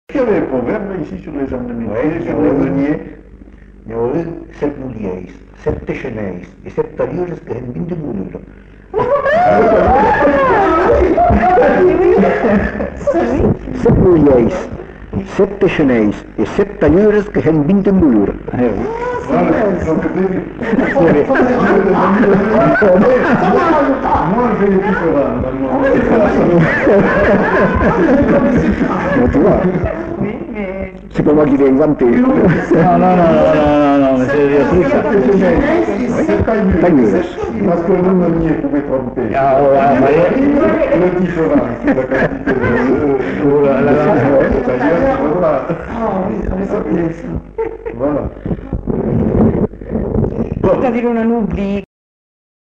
Aire culturelle : Bazadais
Lieu : Uzeste
Type de voix : voix d'homme
Production du son : récité
Classification : proverbe-dicton